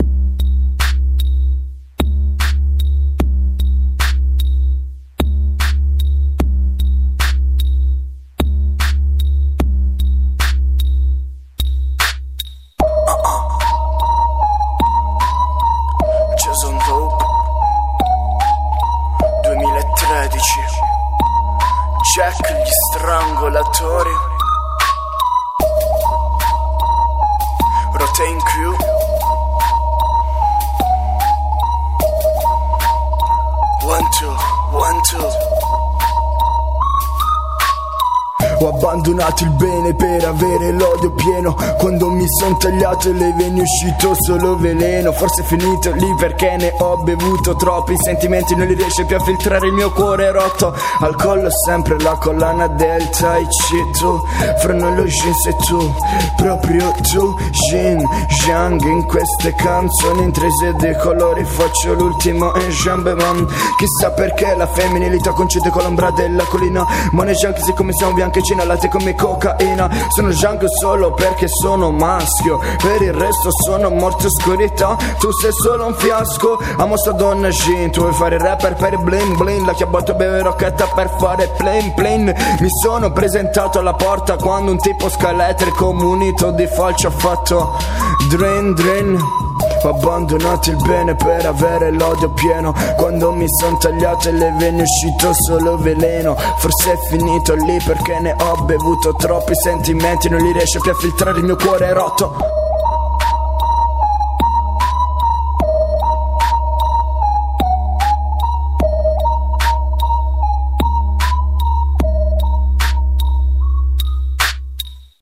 giovane rapper sammichelano
14 pezzi di sua composizione registrati homemade.